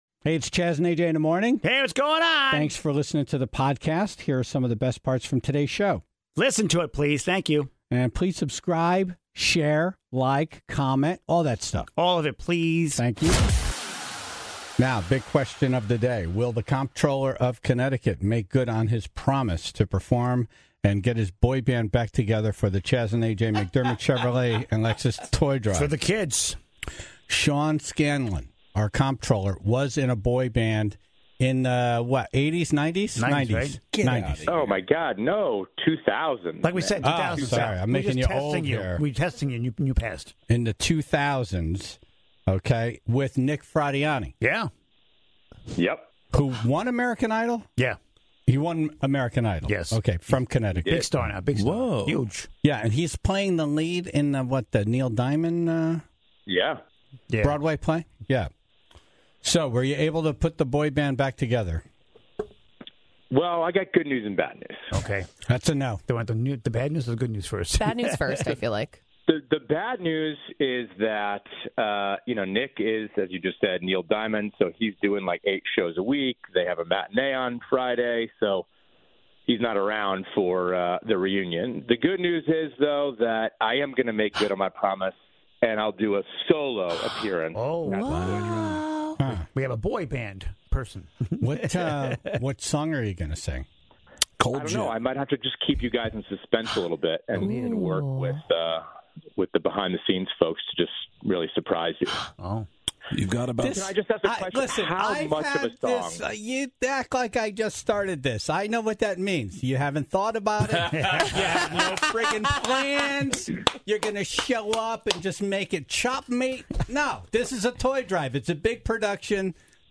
The Tribe called in their stories of the cops showing up to family events, with one caller seemingly have that be a regular occurrence for EVERY holiday. (12:21) Dumb Ass News - Who would spend over $7,000 on a single sandwich?